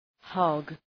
Προφορά
{hɒg}